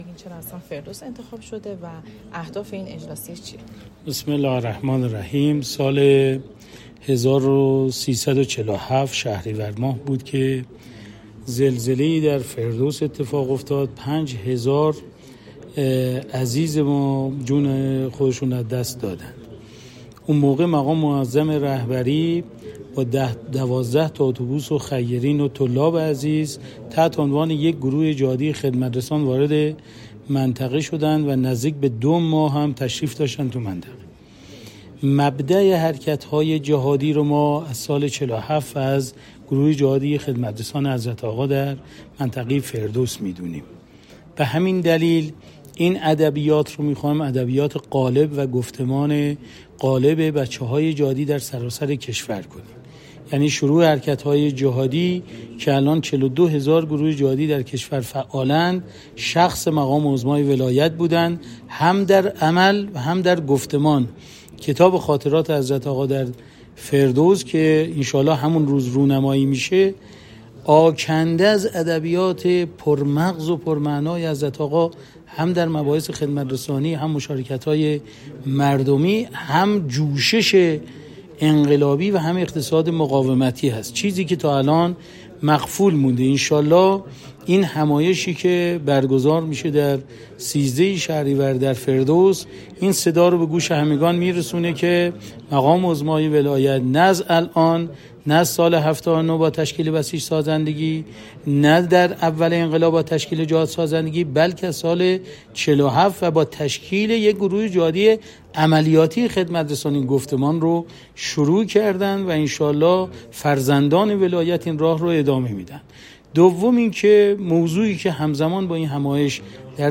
مصاحبه اختصاصی؛ سردار زهرایی مسئول سازمان بسیج سازندگی در حاشیه جلسه هماهنگی برگزاری اجلاسیه ملی جهادگران در شهرستان فردوس
لینک صوتی مصاحبه: